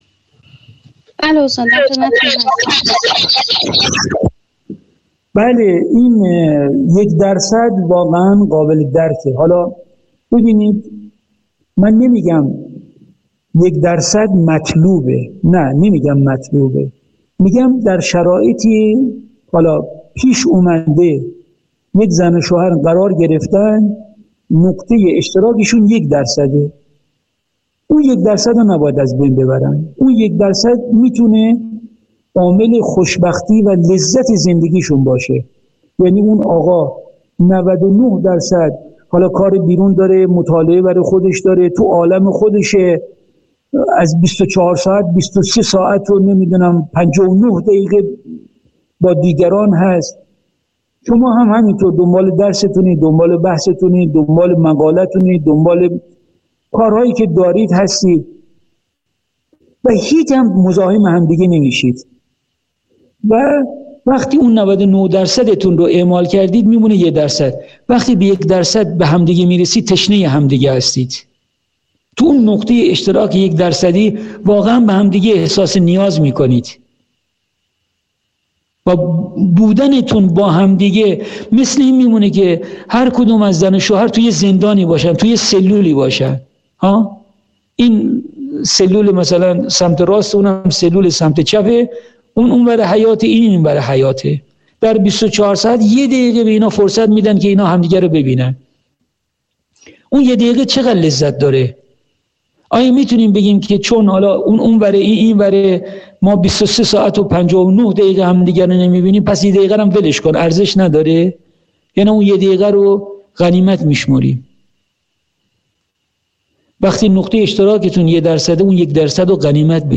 درس 28